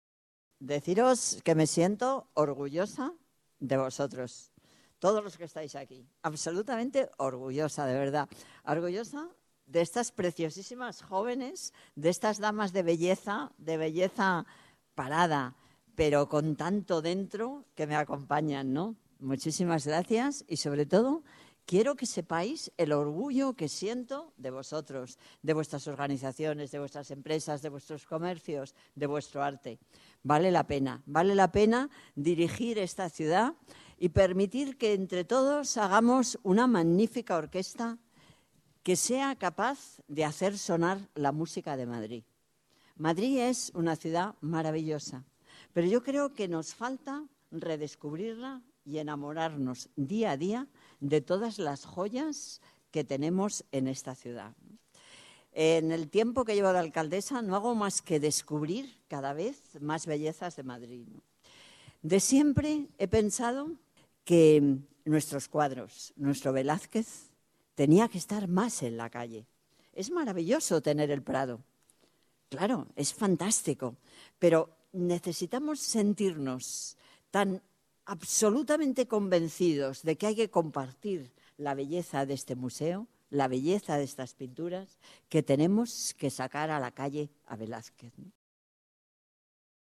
Nueva ventana:Manuela Carmena, alcaldesa de Madrid